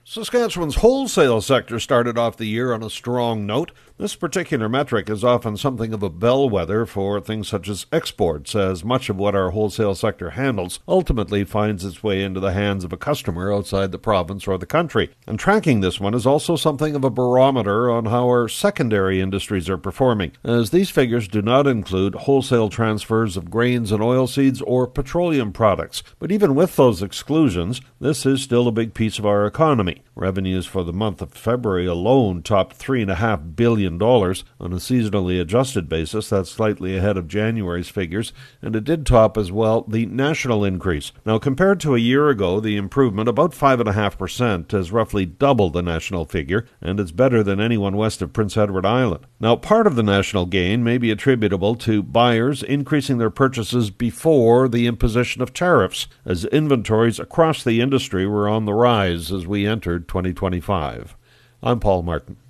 Business Commentary